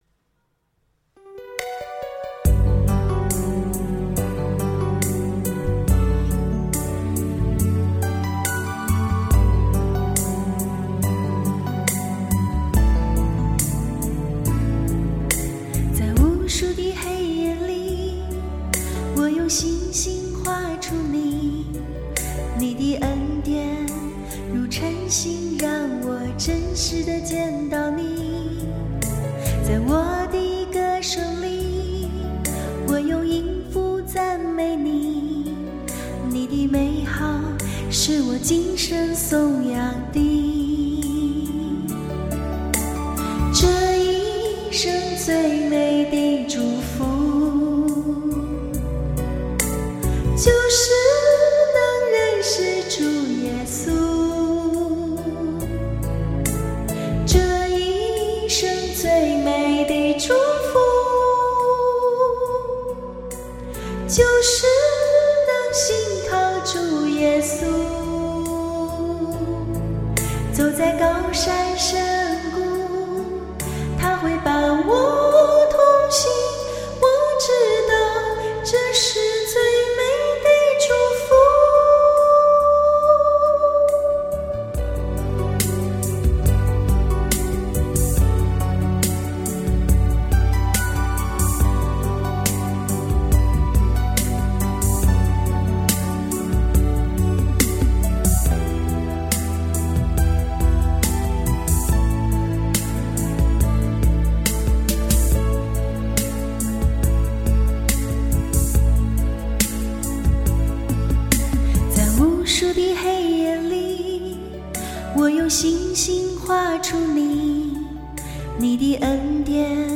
敬拜赞美